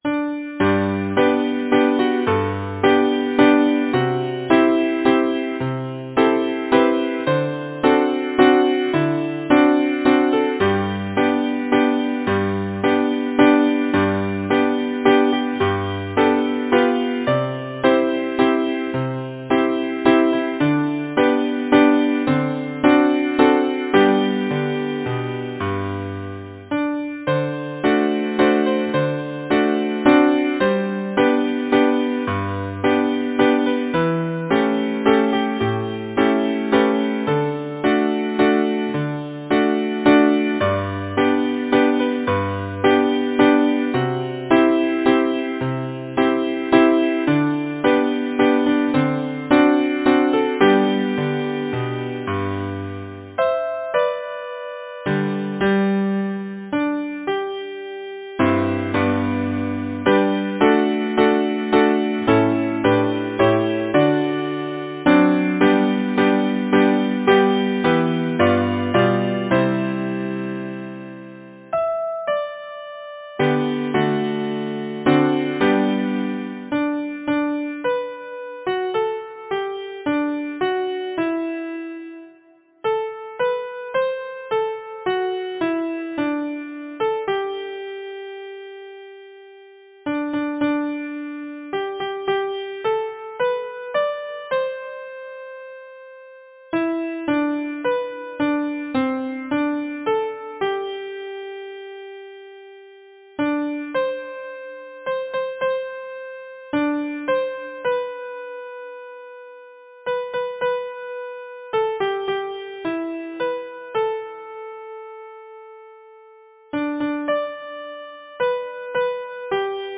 Title: Be True Composer: Jennie M. Fleck Lyricist: Number of voices: 4vv Voicing: SATB Genre: Secular, Partsong
Language: English Instruments: A cappella
First published: 1894 J. F. King Description: (Waltz Song)